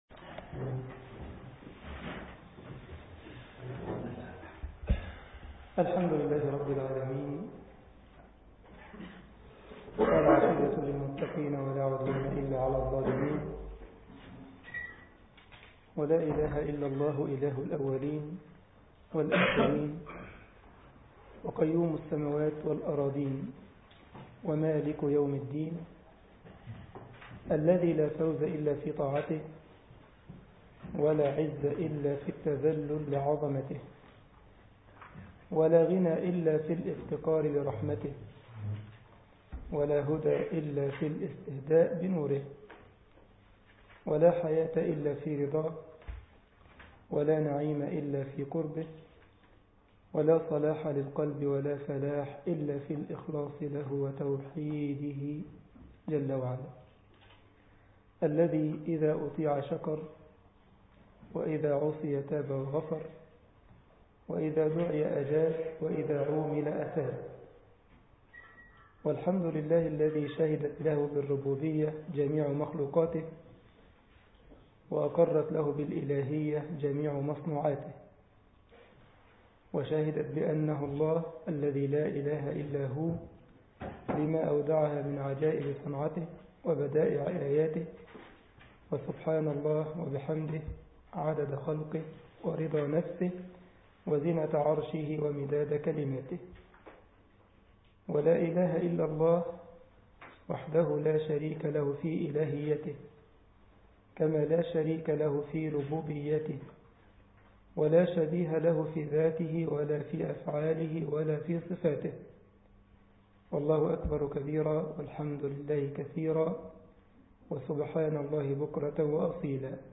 مسجد الجمعية الإسلامية بالسارلند ـ ألمانيا درس الاثنين